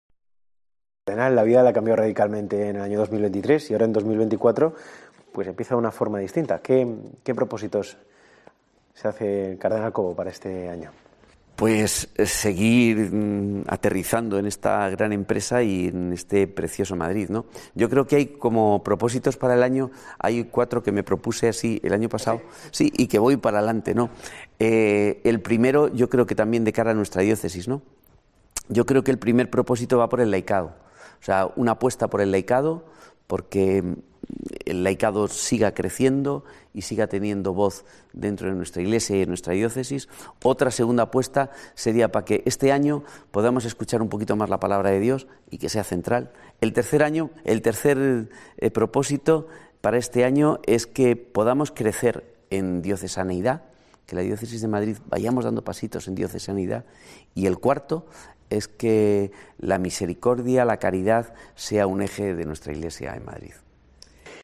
En la última parte de la entrevista en COPE, Cobo ha querido también lanzar un mensaje para aquellas personas que sienten alejadas de la Iglesia:...